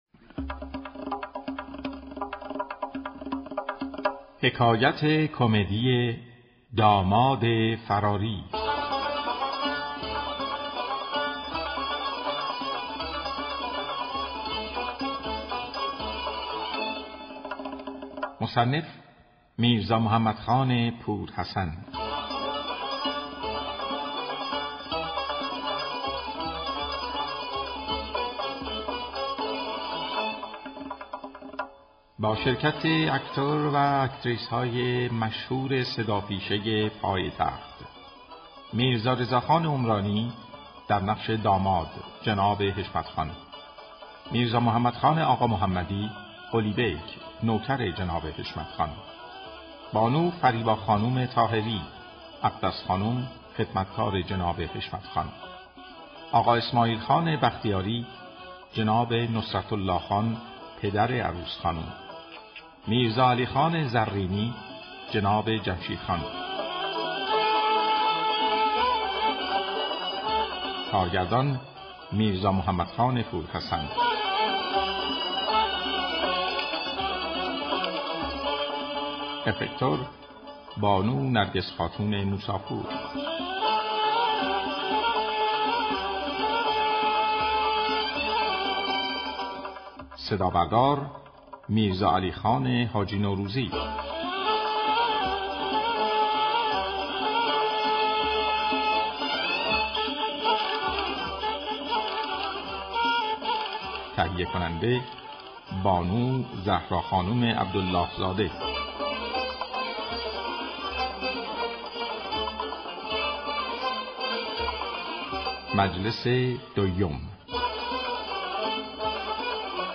از بیستم تیرماه ، سریال طنز رادیویی